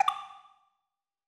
arcade-select.mp3